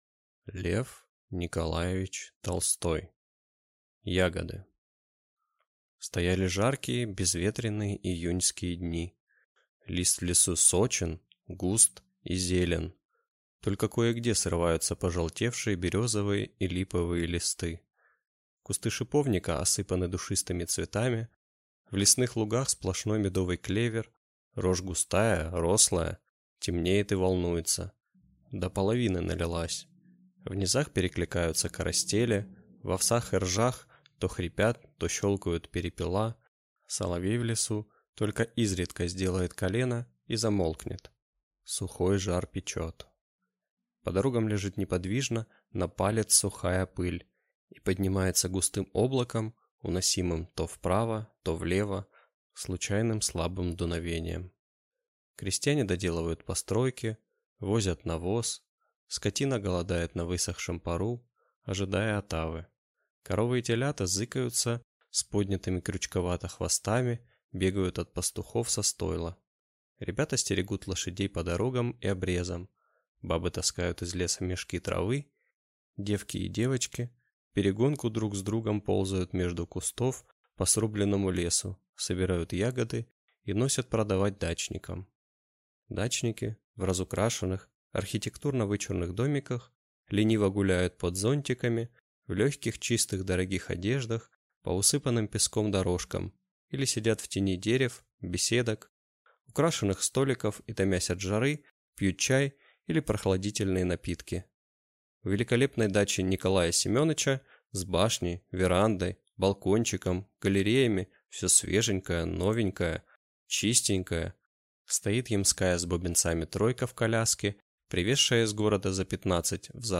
Аудиокнига Ягоды | Библиотека аудиокниг
Прослушать и бесплатно скачать фрагмент аудиокниги